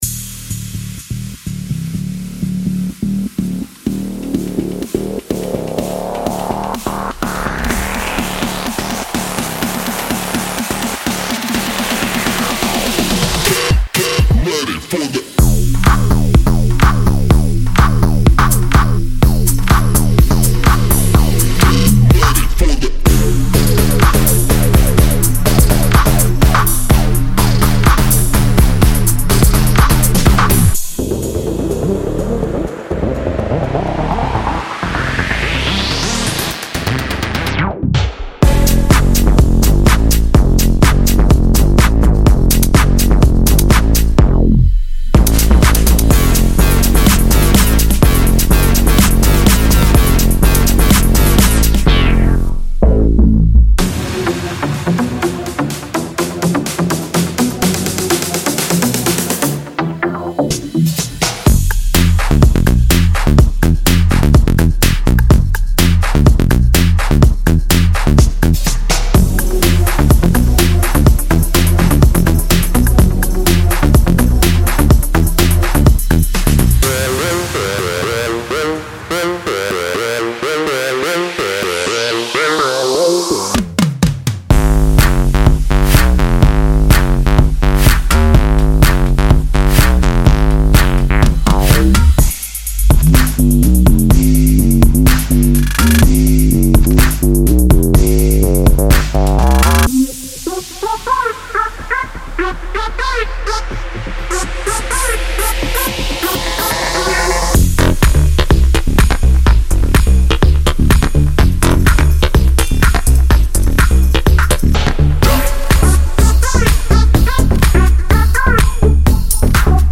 适用于制作Bass House，车库，Electro House，Moombah和其他Bass子流派！
•20个低音循环
•20个合成循环
•10个声乐循环
•20个鼓循环（10个内部循环，10个陷波循环）